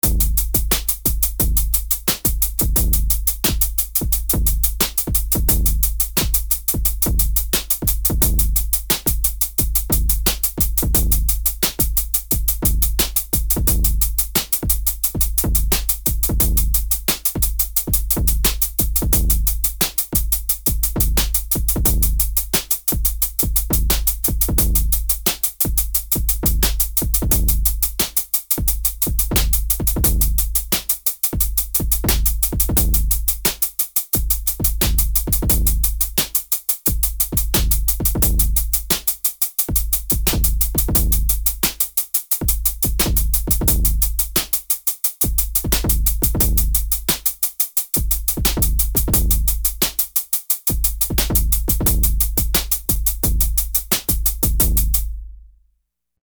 NOTE: The snare lands on the 2nd and 4th beat, and remains the same throughout this entire article.
In the examples below, the Shape function is applied to the kick drum, while leaving the hi-hats in their normal state.
Kick Shape Up
Because the kick drum pattern is more sparse and syncopated than the hi-hat, the rhythms that Shape produces can be more erratic and unpredictable.